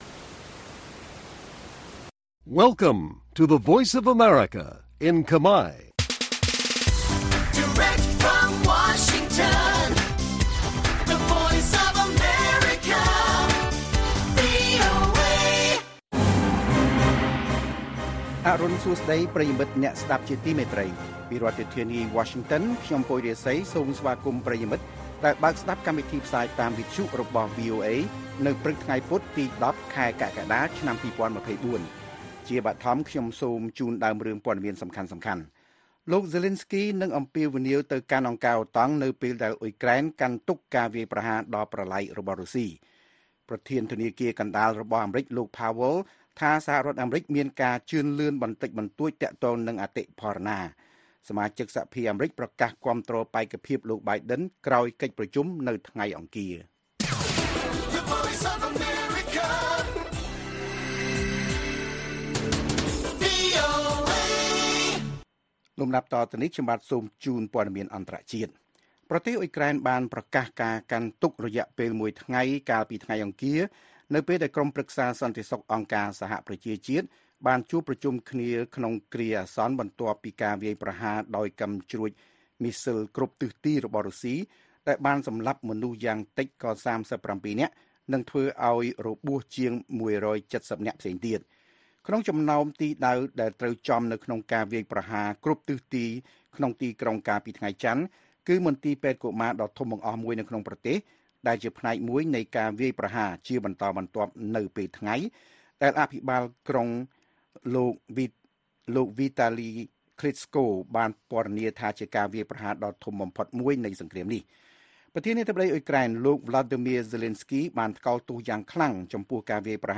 ព័ត៌មានពេលព្រឹក ១០ កក្កដា៖ សមាជិកសភាអាមេរិកប្រកាសគាំទ្របេក្ខភាពលោក Biden ក្រោយកិច្ចប្រជុំនៅថ្ងៃអង្គារ